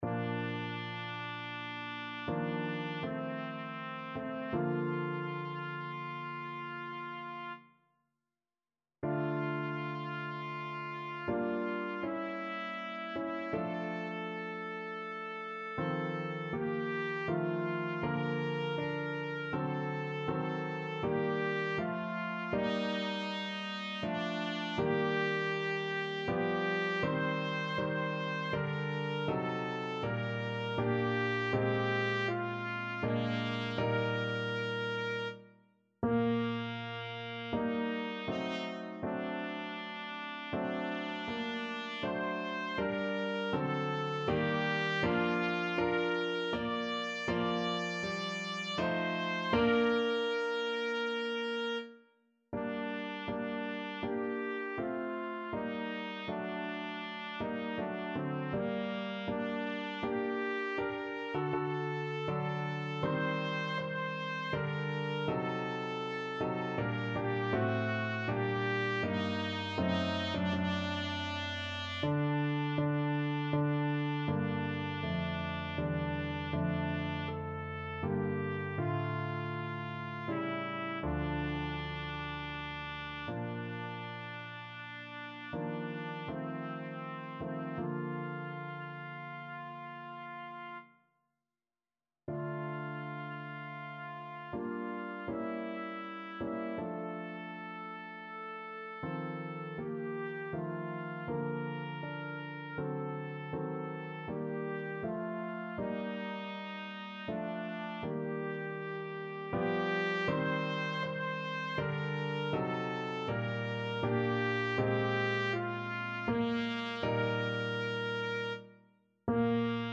3/4 (View more 3/4 Music)
~ = 80 Andante ma non lento
Classical (View more Classical Trumpet Music)